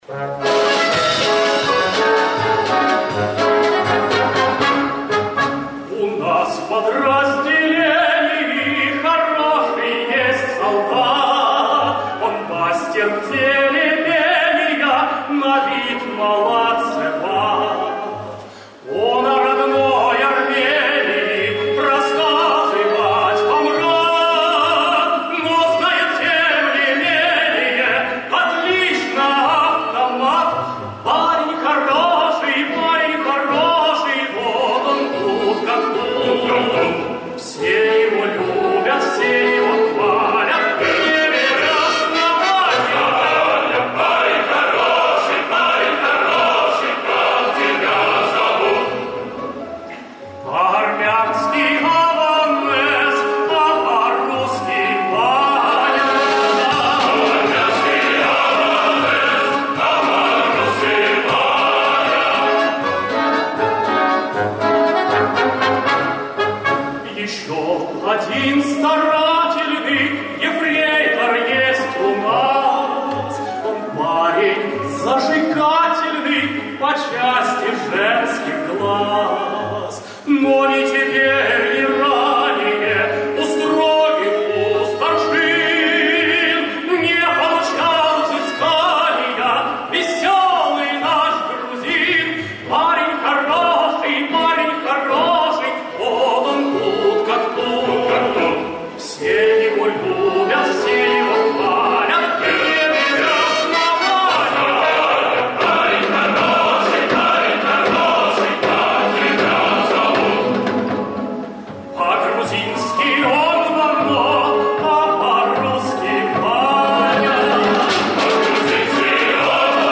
Источник CamRip